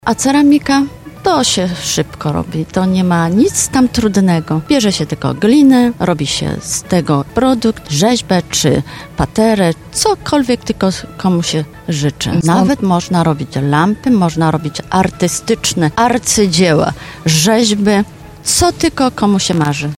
To punkt obowiązkowy przed świętami. Jarmark Bożonarodzeniowy i program na żywo w RDN Nowy Sącz